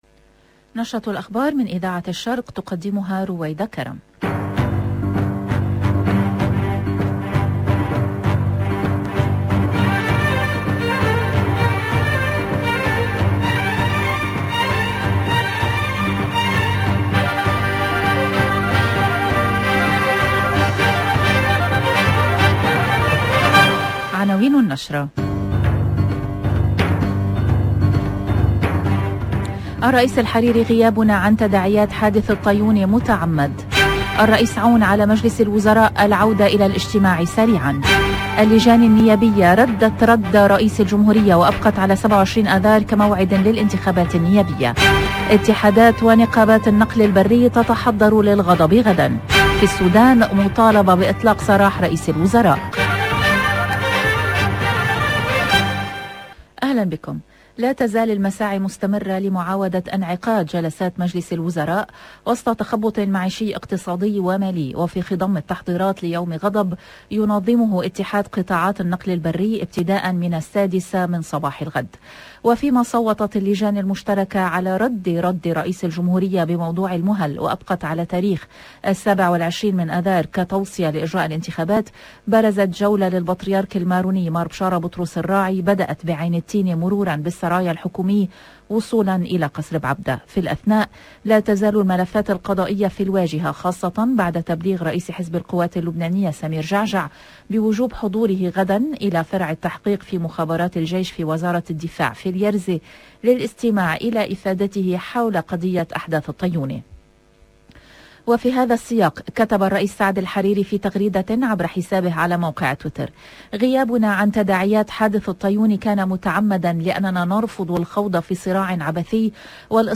EDITION DU JOURNAL DU SOIR EN LANGUE ARABE